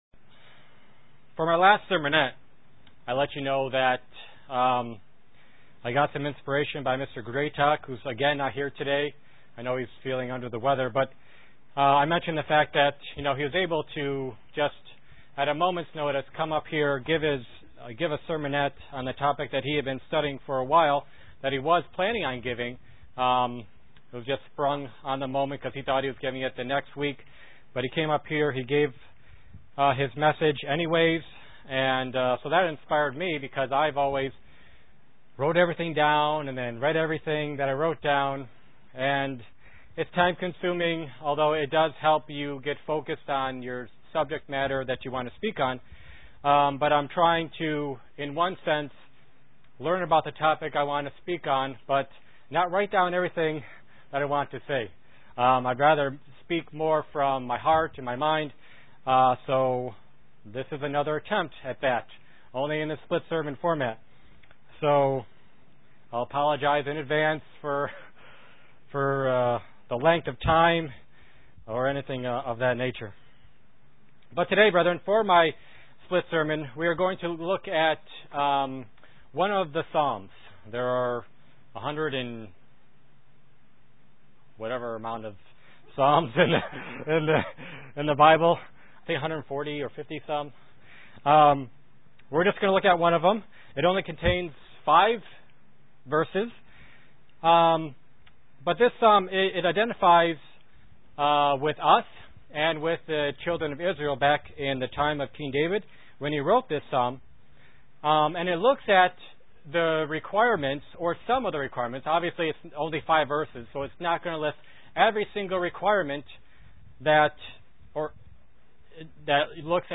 Print Tabernacle a God's dwelling place UCG Sermon Studying the bible?
Given in Elmira, NY